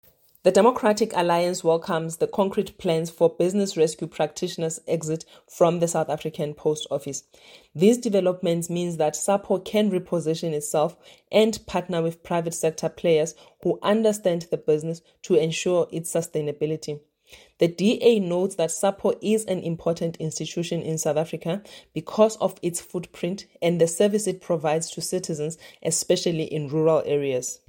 Sesotho soundbites by Tsholofelo Bodlani MP